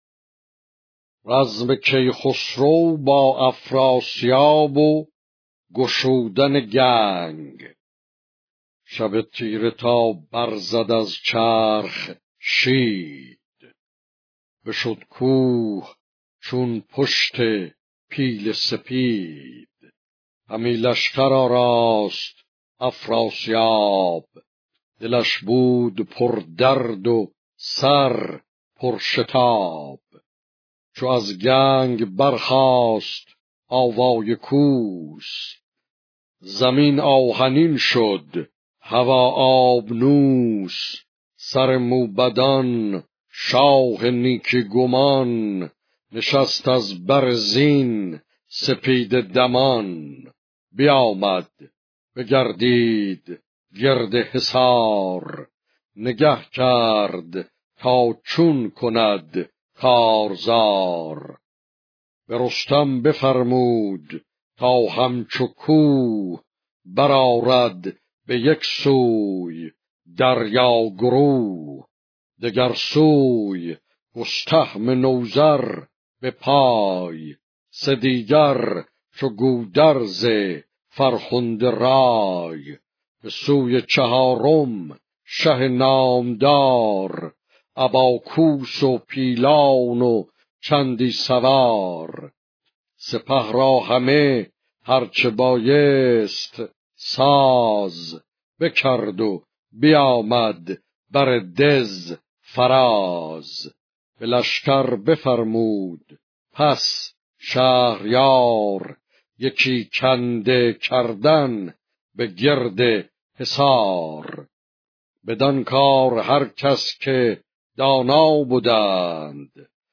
شاهنامه با صدای استاد کزازی - قسمت صد و بیست و ششم - ویکی شاهنامه
شاهنامه خوانی با صدای استاد سید جلال الدین کزازی